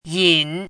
yǐn
拼音： yǐn
注音： ㄧㄣˇ,ㄧㄣˋ
yin3.mp3